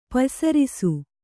♪ paysarisu